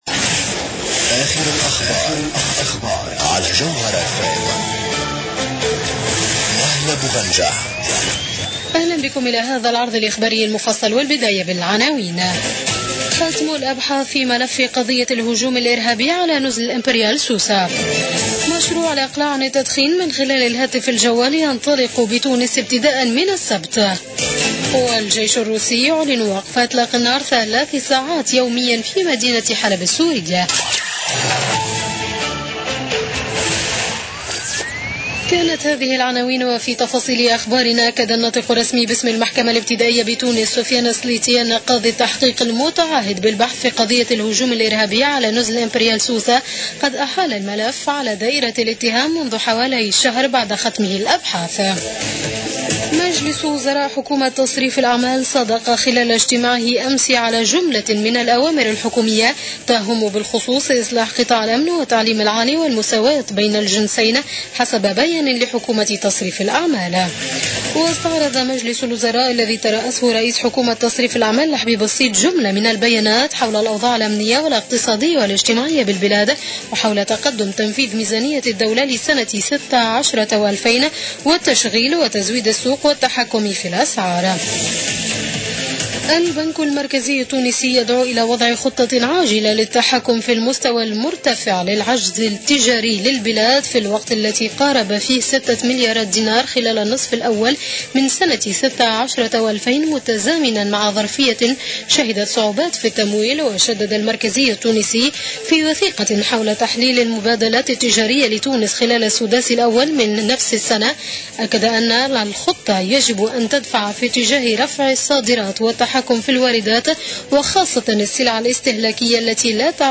Journal Info 00h00 du jeudi 11 août 2016